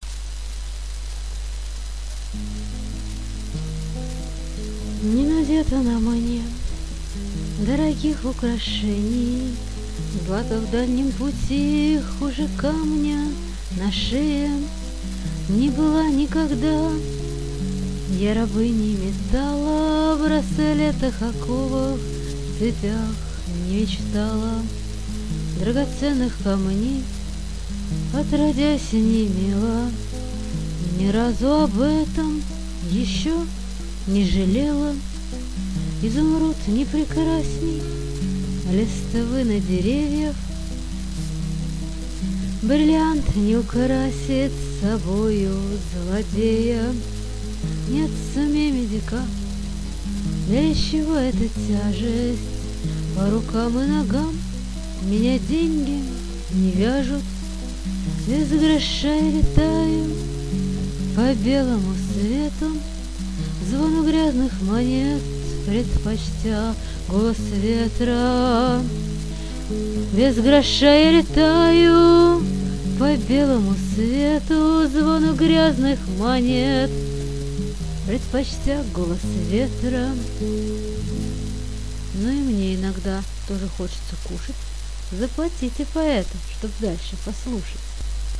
Потом появилось два исполнения.